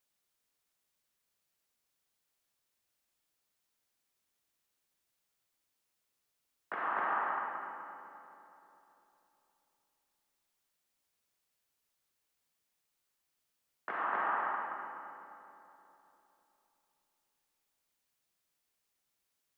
Boomin-Beat-Starter-0_Clap Reverb.wav